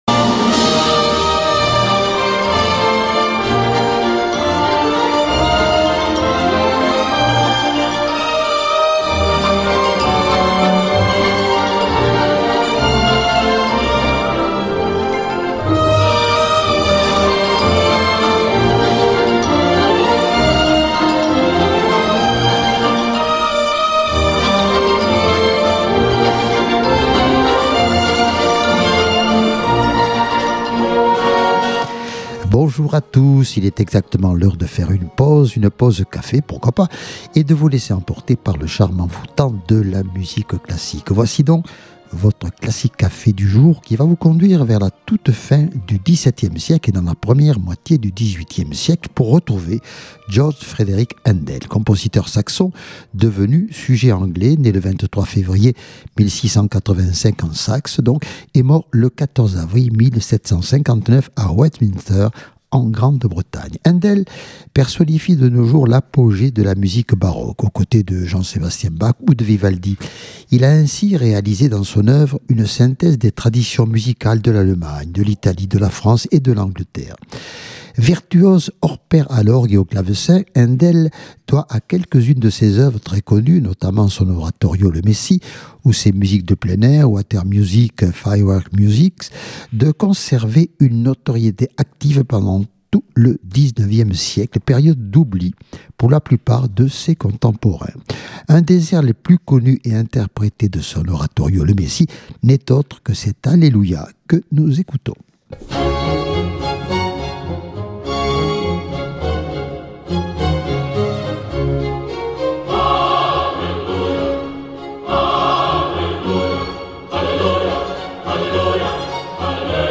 Classique Café une émission Musicale